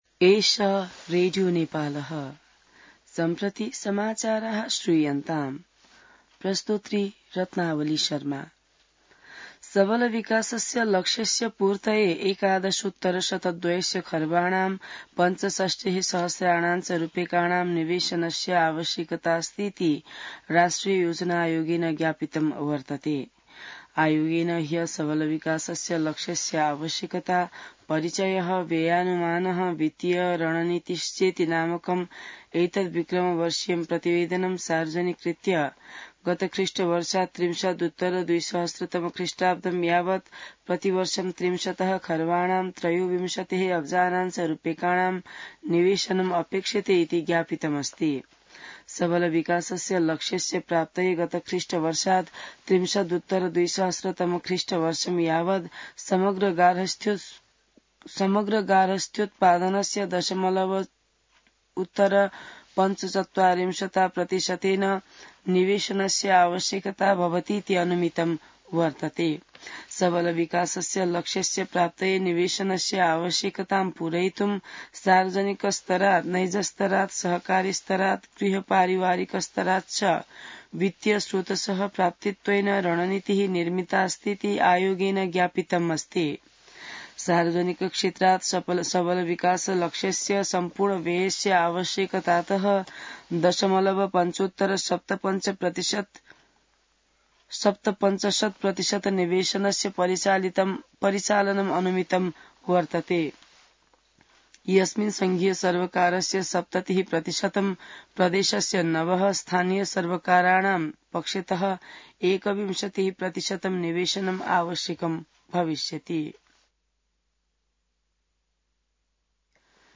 An online outlet of Nepal's national radio broadcaster
संस्कृत समाचार : ८ वैशाख , २०८२